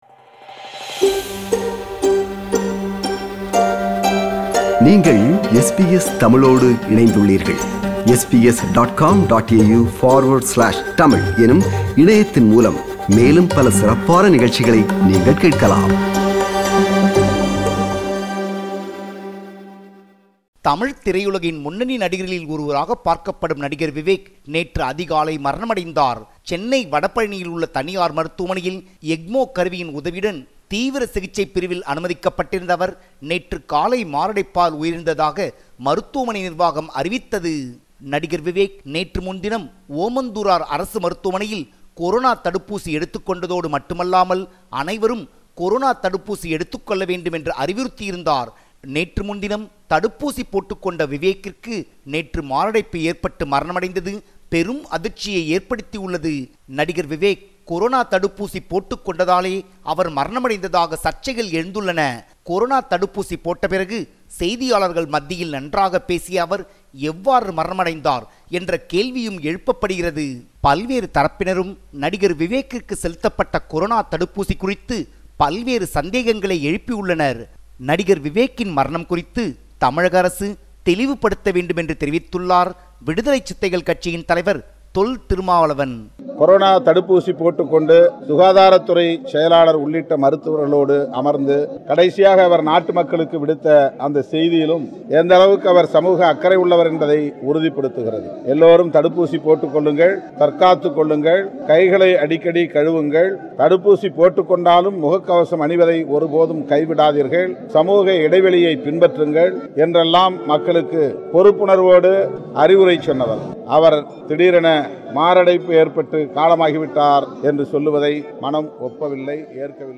தடுப்பூசிக்கும் இதற்கும் சம்பந்தம் இல்லையென மருத்துவமனை நிர்வாகம் மற்றும் தமிழக அரசின் சுகாதாரத்துறை தெரிவித்துள்ளது. கூடுதல் விவரங்களுடன் இணைகிறார் நமது தமிழக செய்தியாளர்